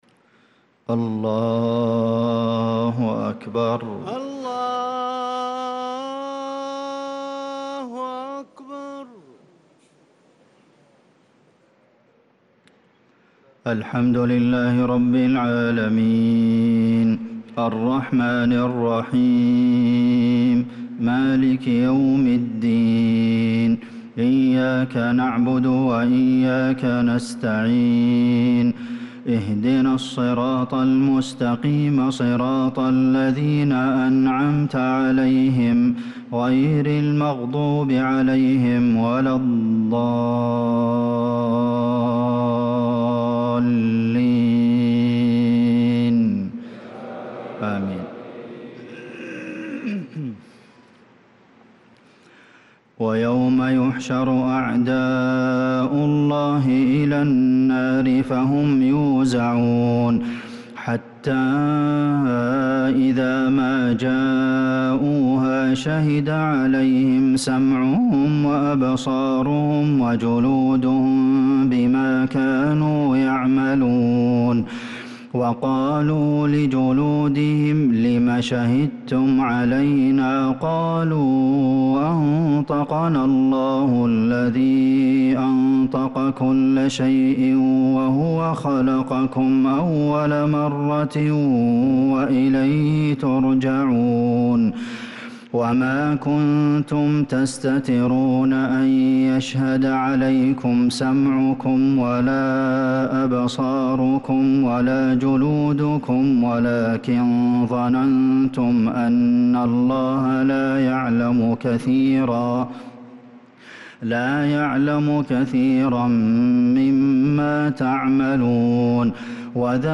صلاة العشاء للقارئ عبدالمحسن القاسم 9 ربيع الأول 1446 هـ
تِلَاوَات الْحَرَمَيْن .